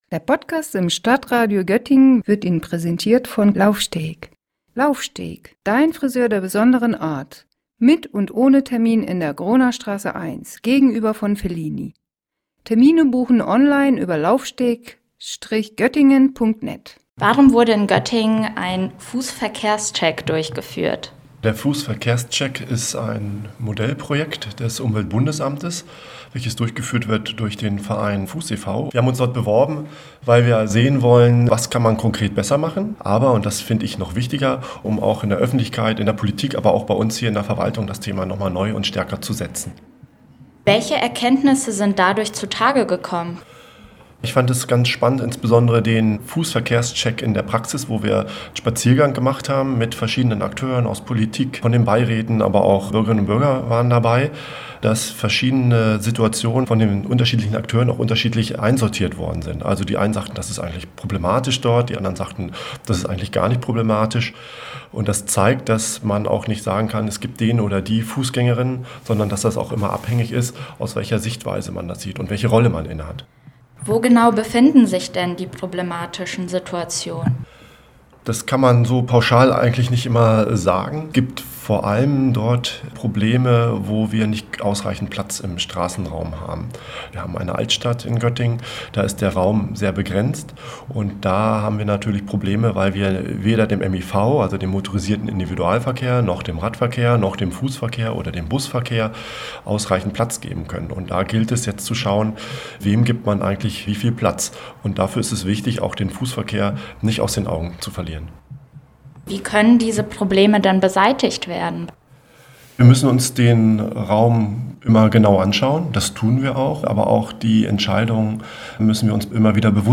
Beiträge > Stadt Göttingen will Verkehrssituation für Fußgänger verbessern - StadtRadio Göttingen